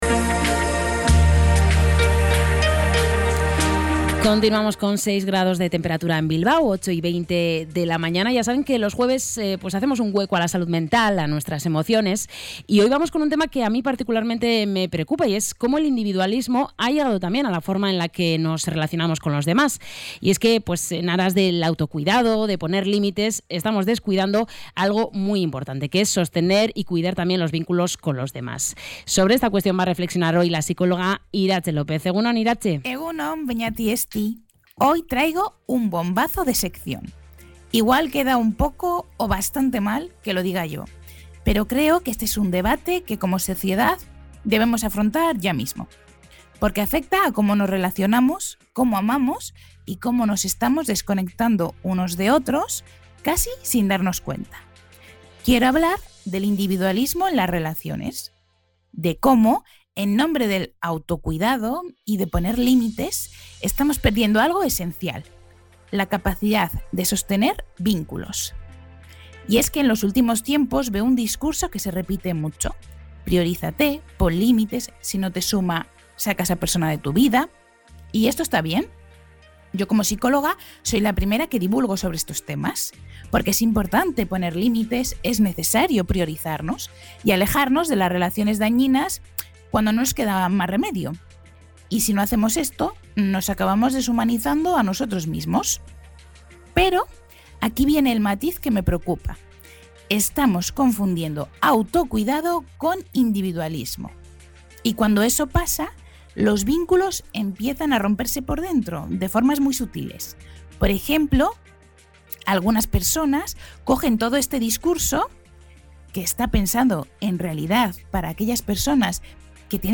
La psicóloga ha invitado a preguntarse desde qué valores se está relacionando cada persona.